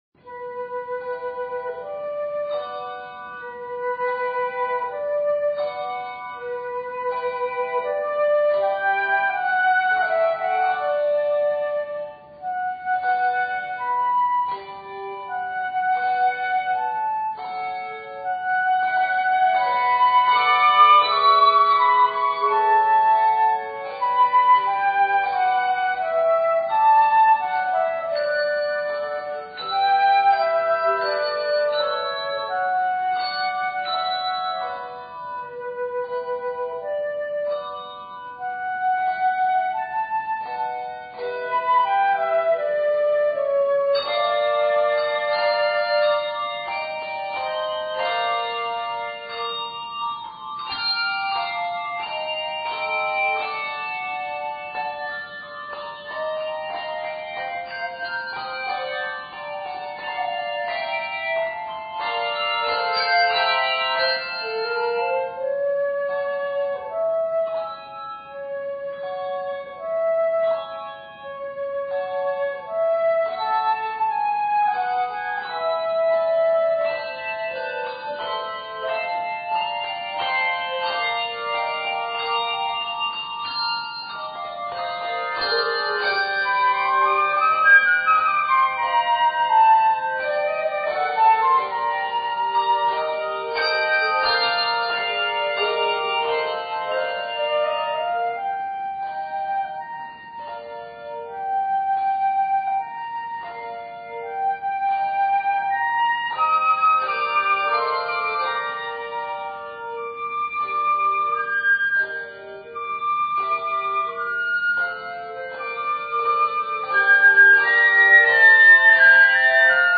Harmonies out of the key add freshness.